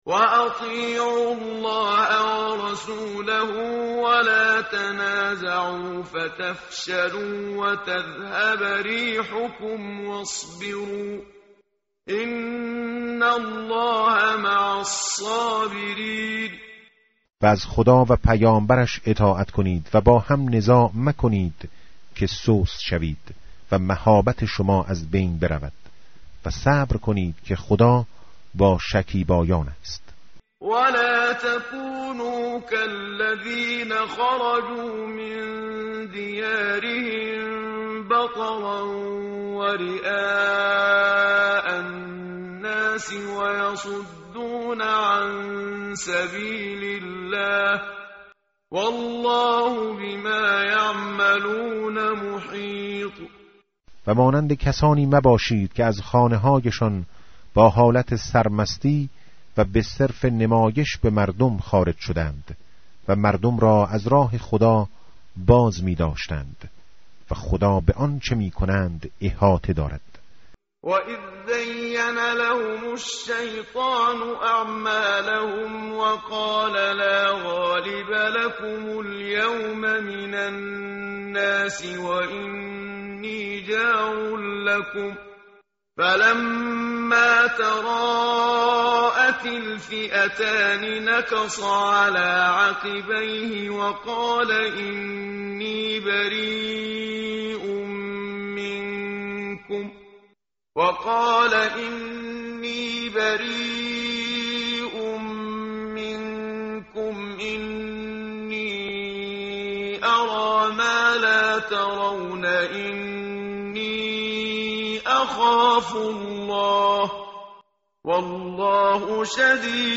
tartil_menshavi va tarjome_Page_183.mp3